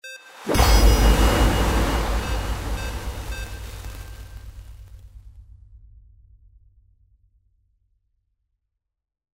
Water Magic Start 05B
Stereo sound effect - Wav.16 bit/44.1 KHz and Mp3 128 Kbps
previewSCIFI_MAGIC_WATER_START_WBHD05B.mp3